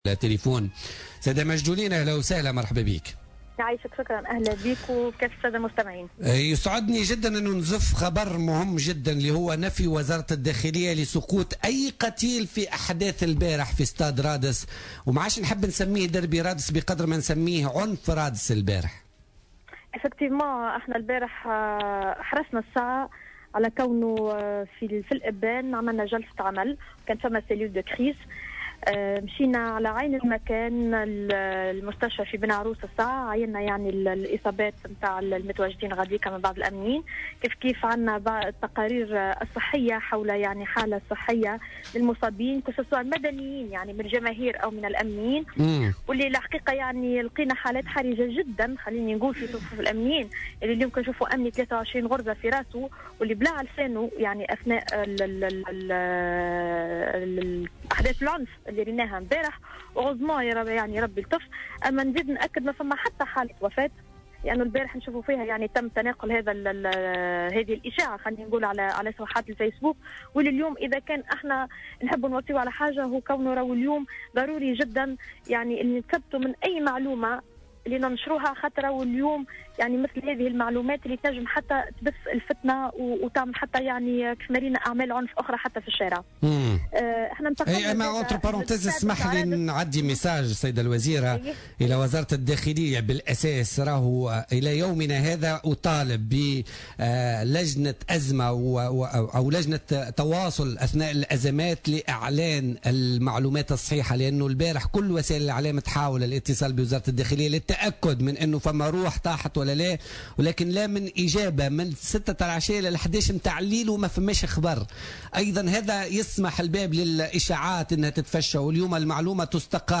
أوضحت وزيرة الشباب والرياضة ماجدولين الشارني في مداخلة لها في بولتيكا اليوم الإثنين 1 ماي 2017 أن العنف الذي تخلل مباراة الدربي ناتج عن غياب التأطير للجماهير محملة كل جمعية مسؤوليتها عما حدث.